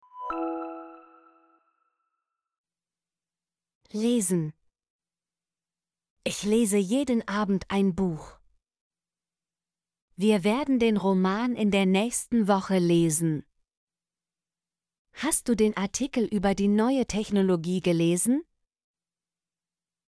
lesen - Example Sentences & Pronunciation, German Frequency List